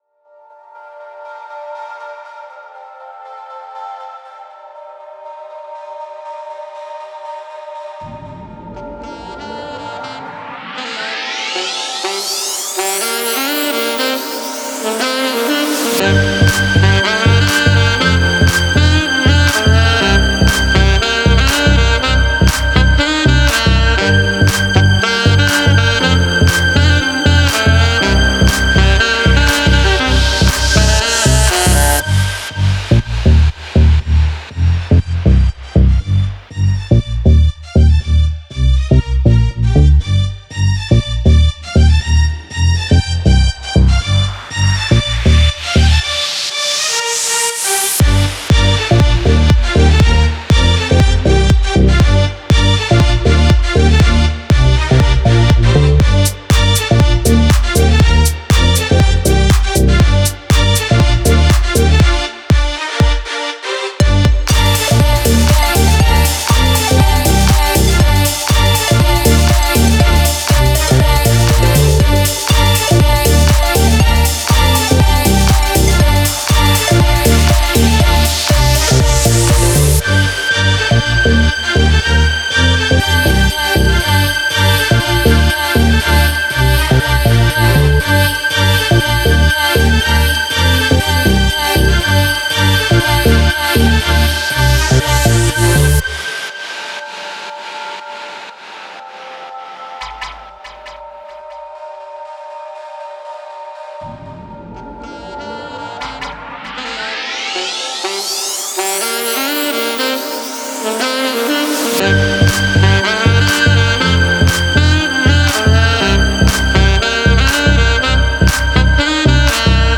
яркая и жизнерадостная композиция
исполненная в жанре поп-рок с элементами электронной музыки.